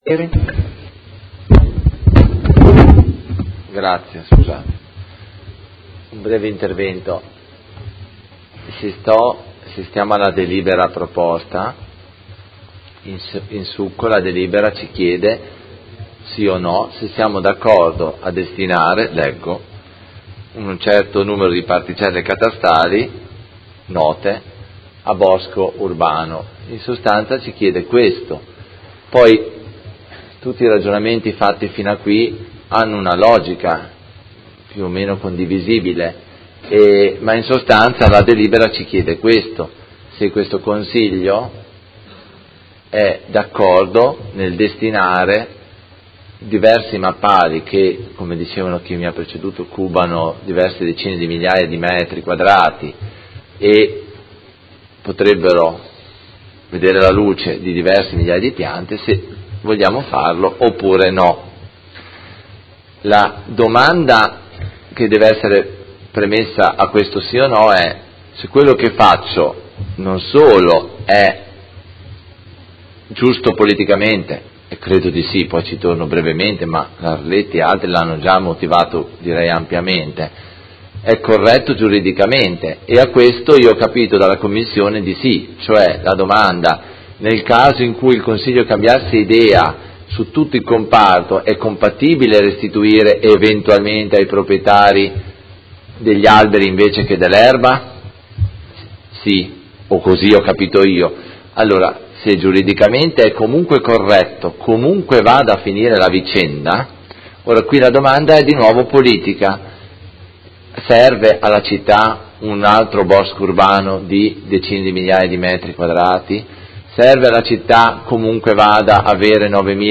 Antonio Carpentieri — Sito Audio Consiglio Comunale
Seduta del 01/02/2018 Dibattito.